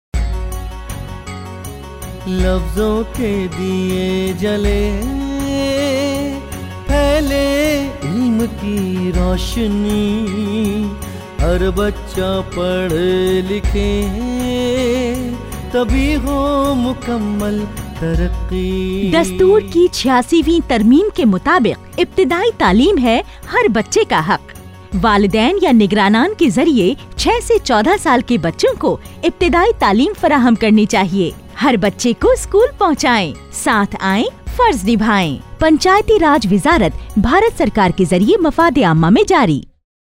243 Fundamental Duty 10th Fundamental Duty Strive for excellence Radio Jingle Urdu